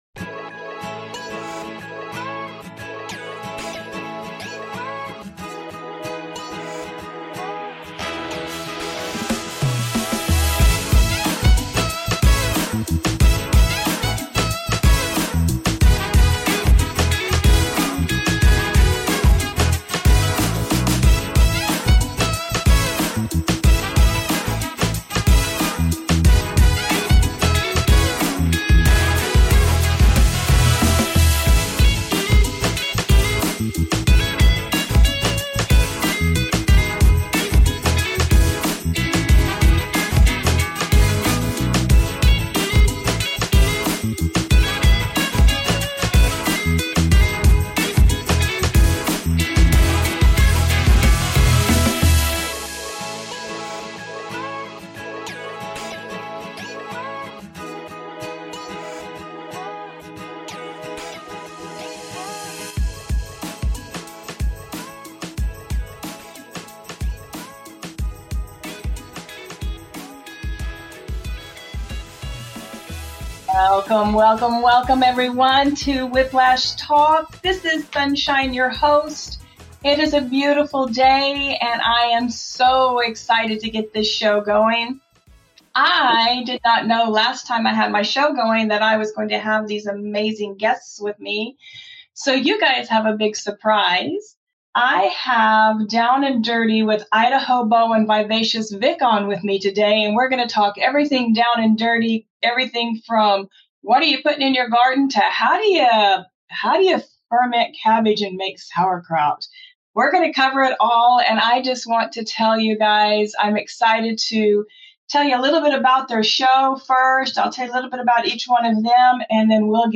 Whiplash Talk